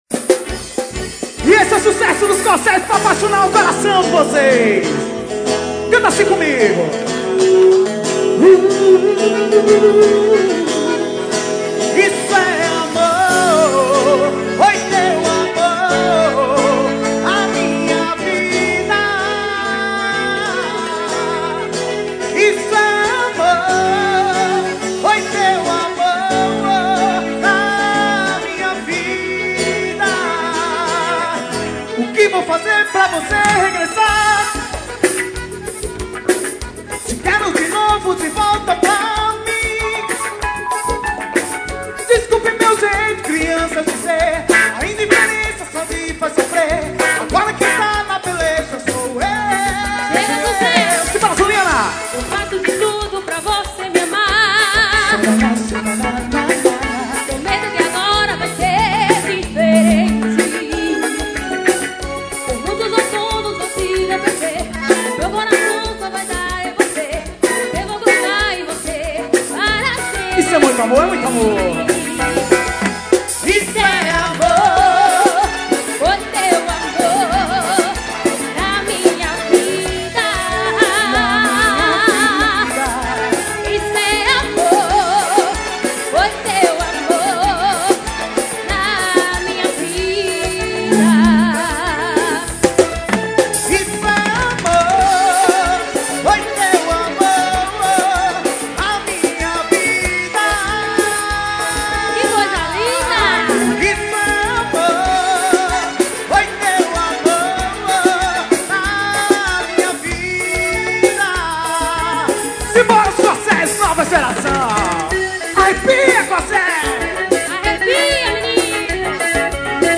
Show em Maraial-PE.